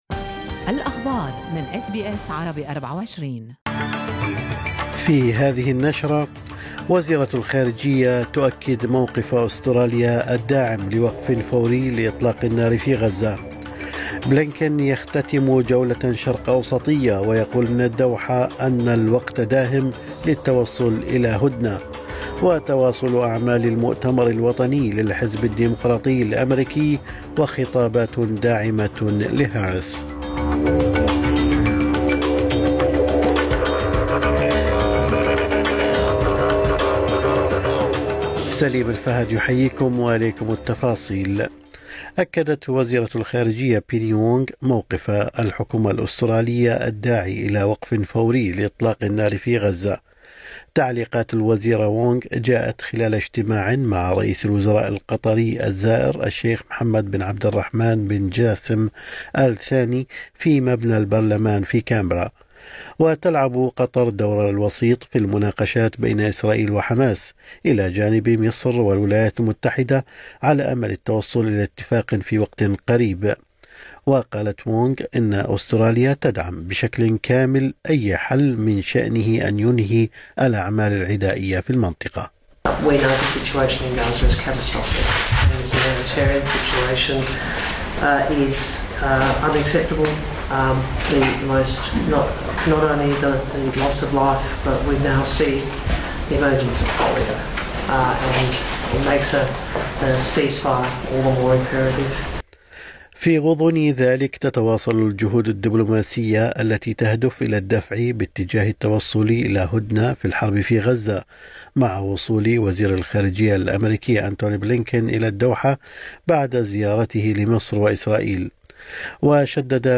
نشرة أخبار الصباح 21/8/2024